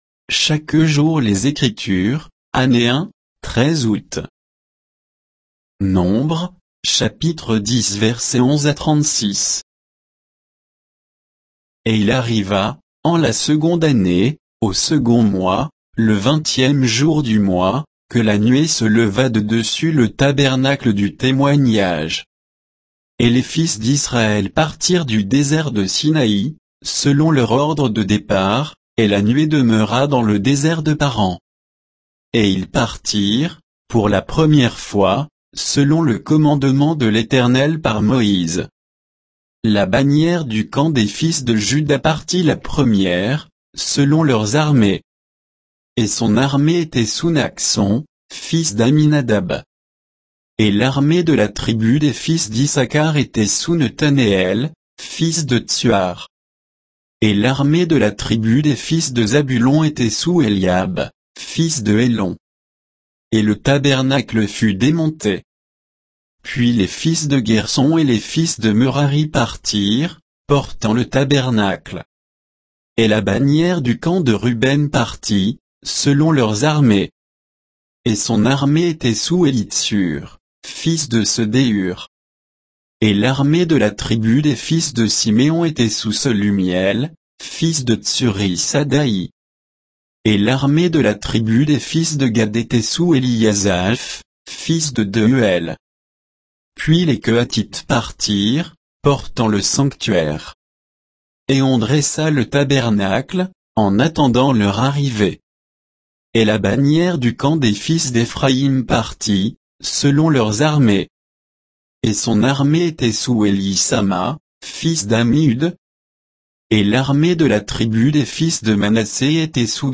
Méditation quoditienne de Chaque jour les Écritures sur Nombres 10